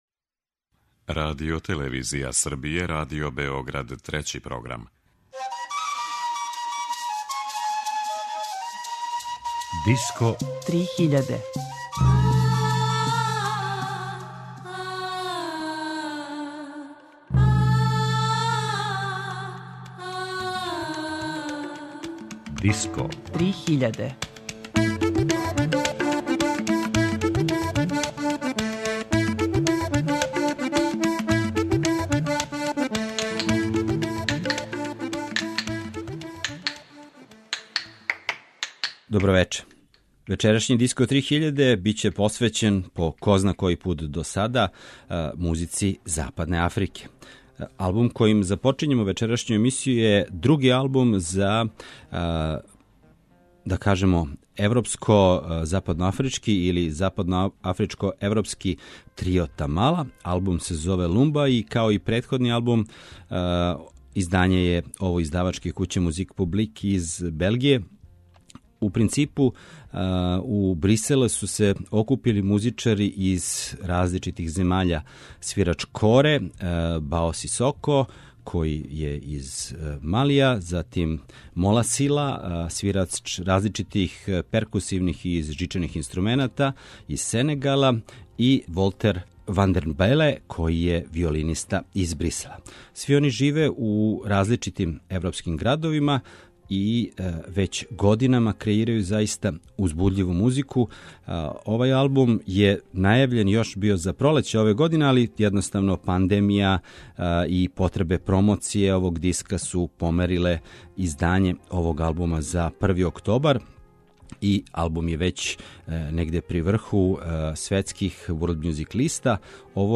world music
коре